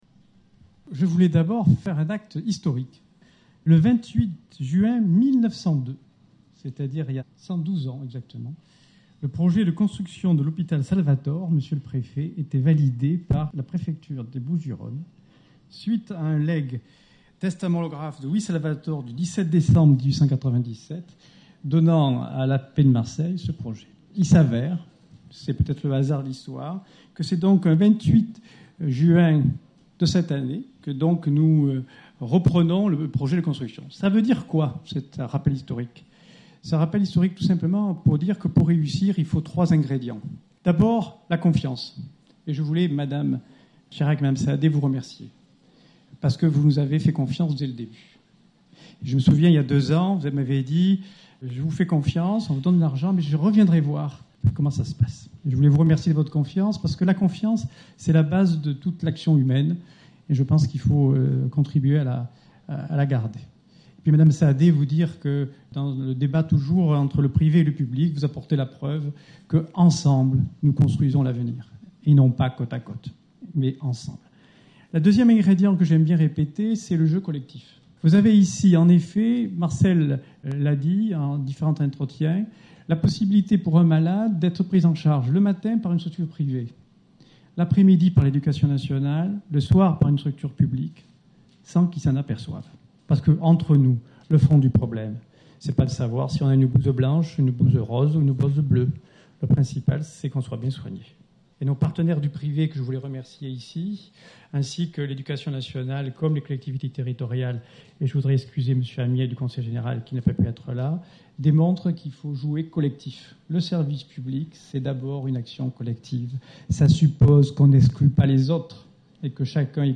Inauguration de l’Espace méditerranéen de l’adolescence.